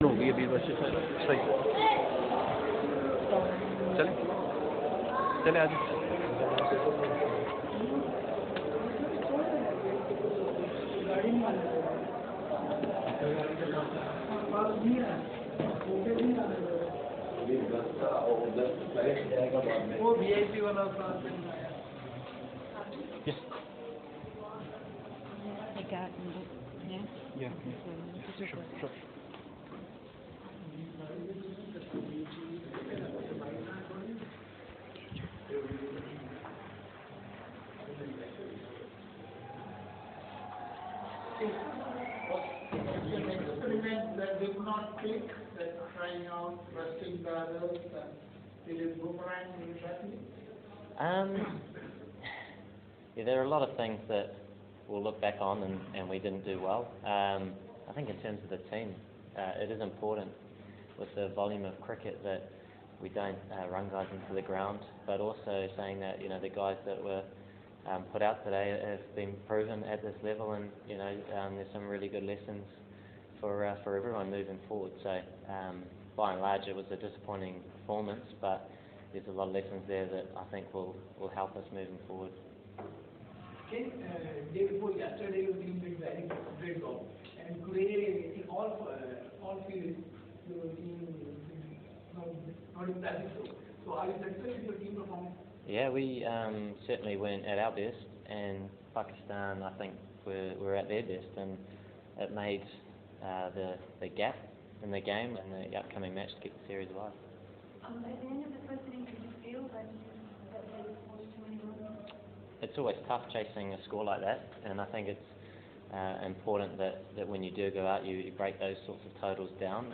Kane Williamson Press Conference post match 3rd ODI at Sharjah (Audio)